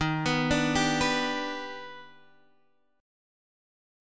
G/Eb chord